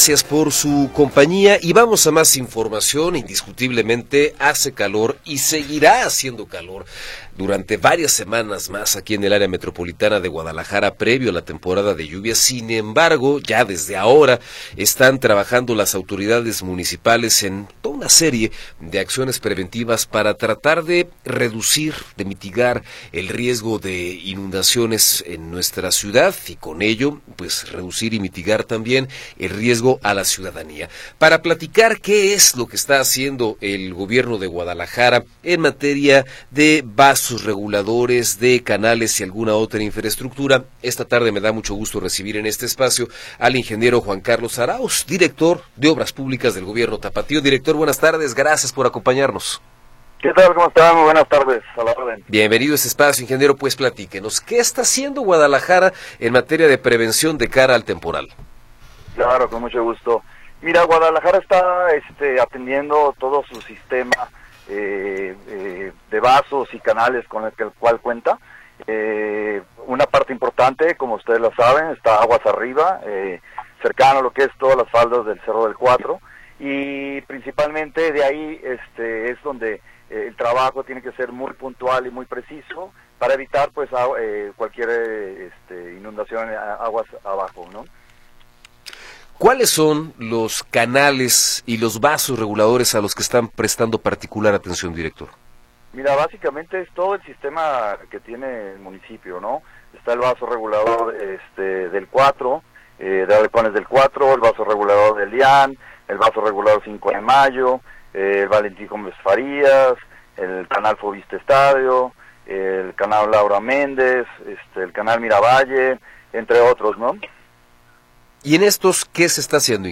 Entrevista con Juan Carlos Arauz